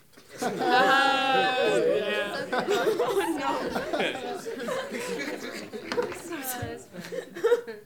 Male Adult Laugh Chuckle Guffaw Chortle